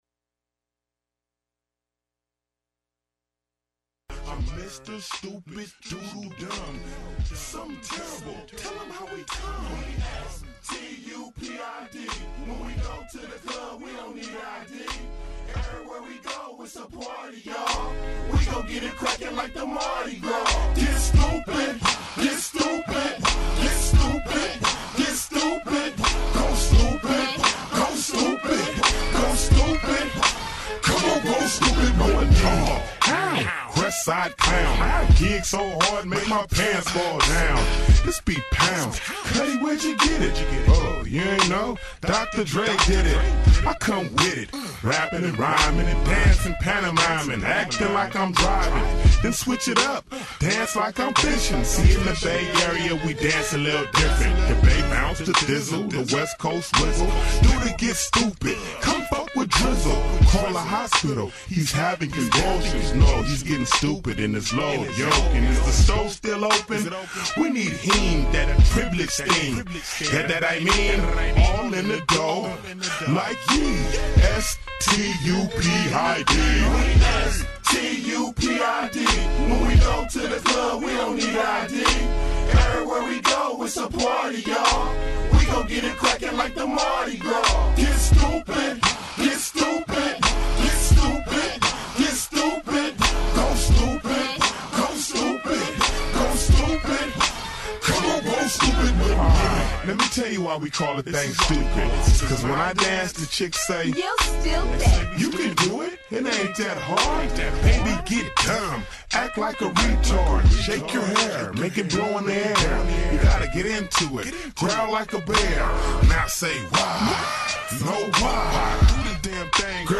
Make sure you tune in every week on Fridays from 6:15 to 7:35 On this show, you’ll hear recent news, personal experiences, and a diverse selection of music. Youth Radio Raw is a weekly radio show produced by Bay Area high schoolers, ages 14-18.
hip hop